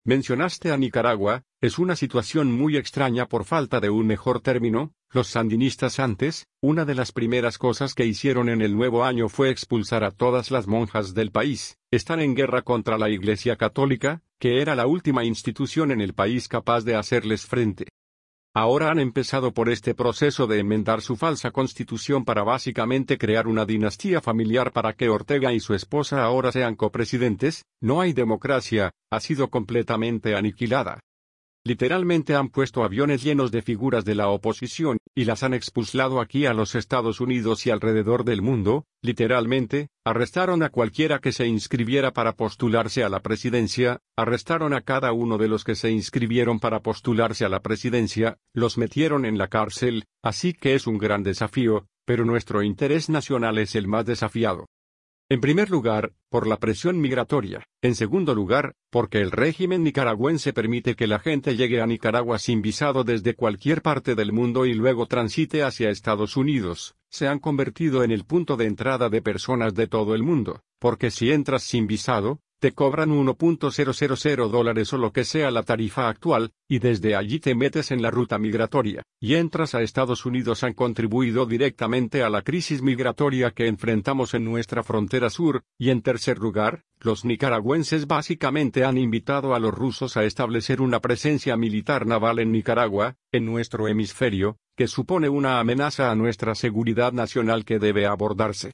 En su comparecencia ante el Senado, Rubio subrayó la persecución religiosa, los destierros masivos, la injerencia rusa en Nicaragua y el rol del régimen nicaragüense en facilitar el paso de migrantes ilegales hacia Estados Unidos.